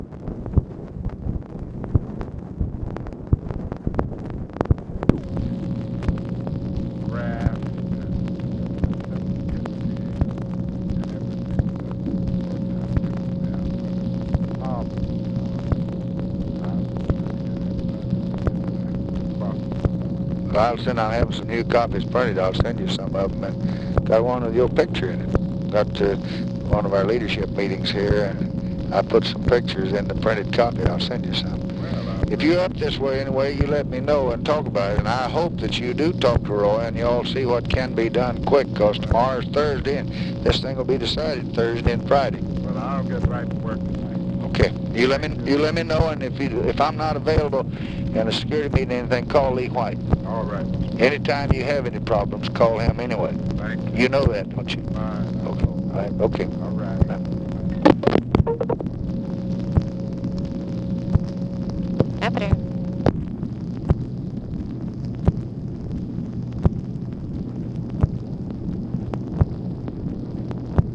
Conversation with MARTIN LUTHER KING, July 8, 1965
Secret White House Tapes